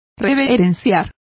Complete with pronunciation of the translation of venerates.